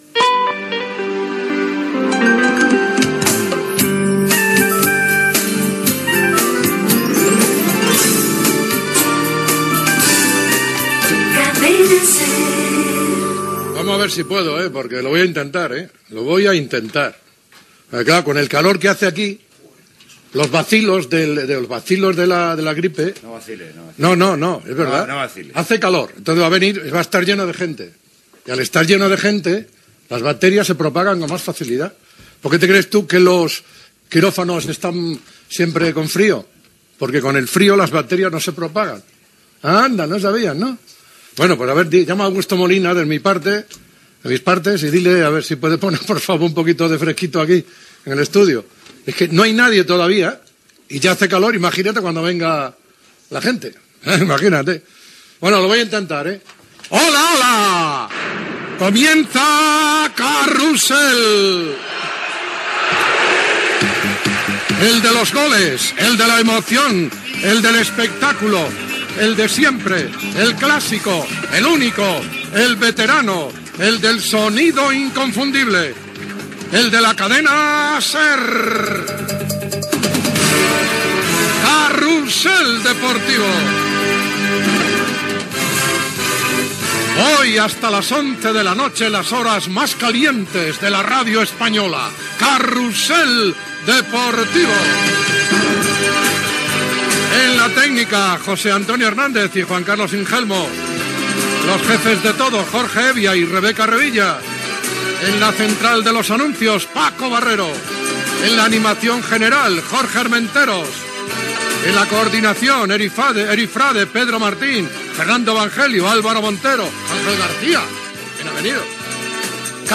Indicatiu de la cadena, comentari sobre la grip i la temperatura de l'estudi, salutació inicial, equip del programa, partits de futbol de la jornada de primera divisió, resultats de la segona divisió, resum dels partits de les lligues europees, resultats de la segona divisió B
Esportiu